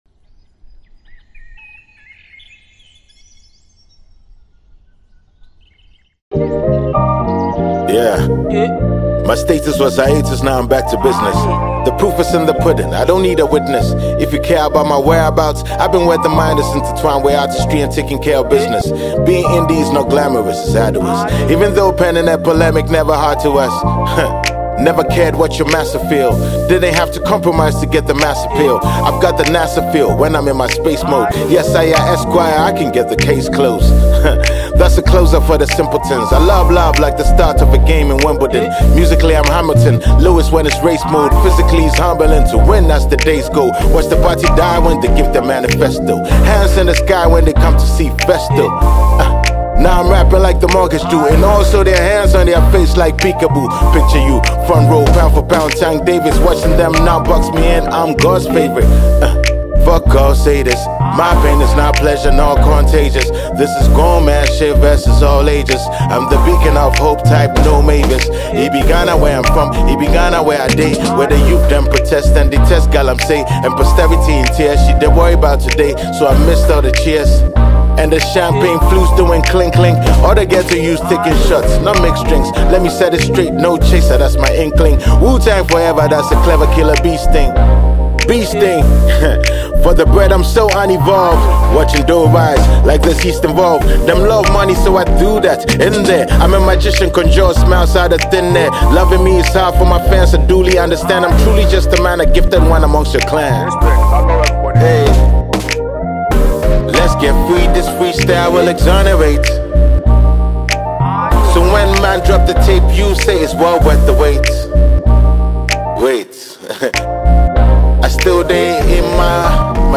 Ghanaian rapper and entrepreneur